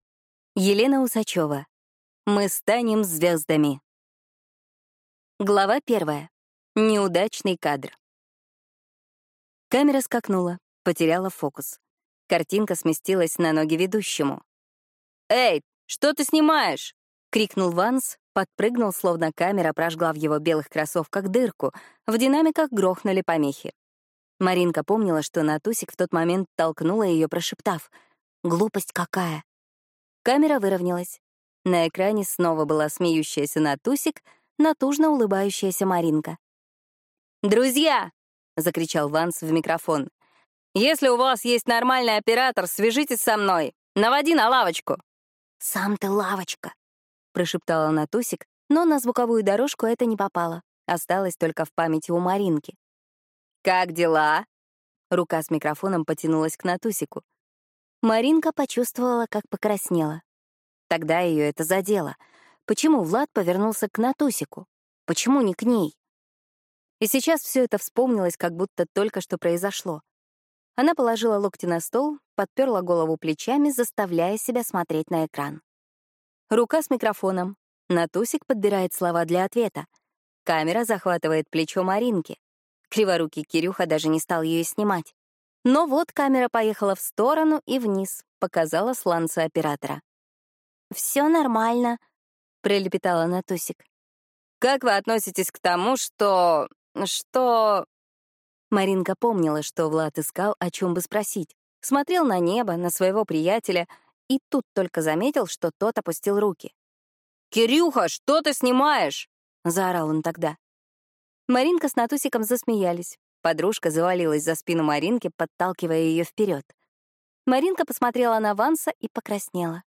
Аудиокнига Мы станем звёздами!